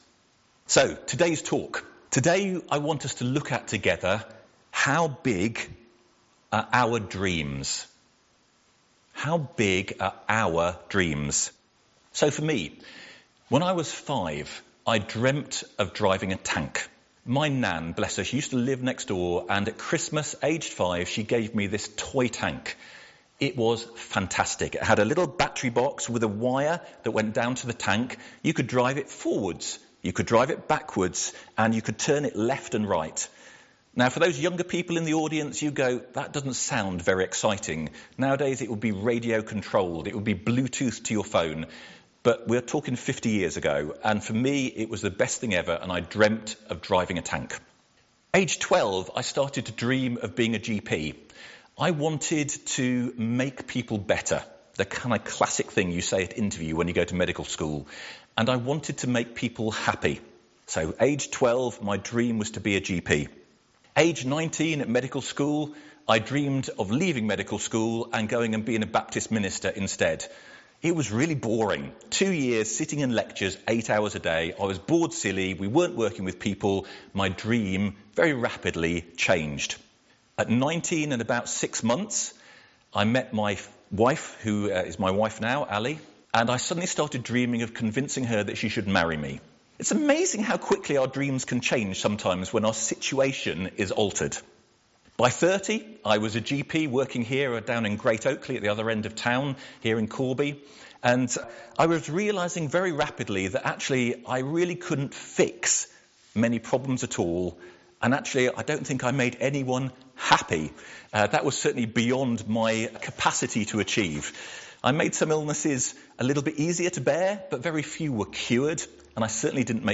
MP3 SUBSCRIBE on iTunes(Podcast) Notes Sermons in this Series Have you ever had a dream to do something or be something? Do you think that our dreams could be put in our heart by God?